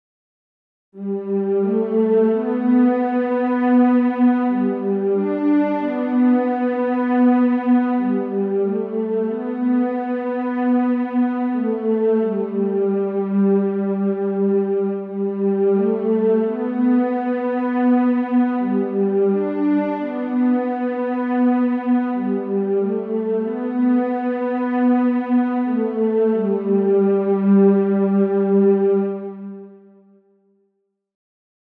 Bridge Strings.wav